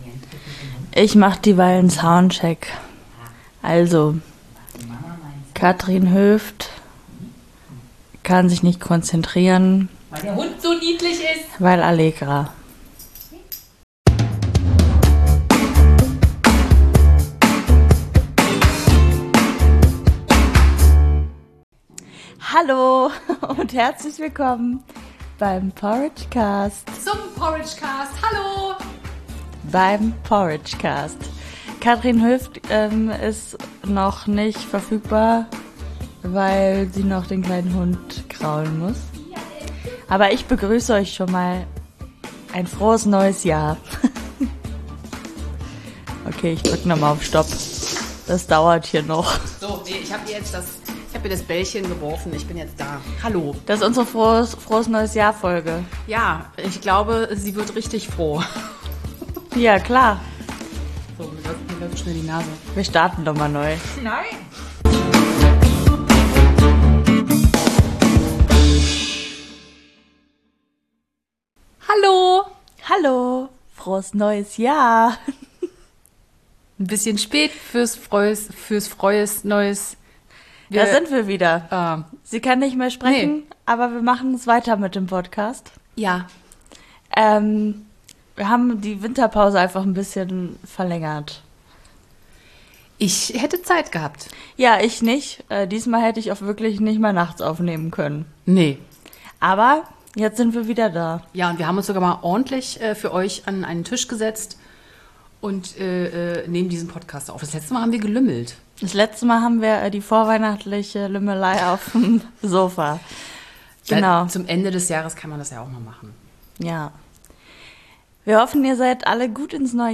Und voller Tatendrang, auch wenn man uns das in dieser Folge nicht durchgehend anhört ;) Schön, dass ihr dabei seid!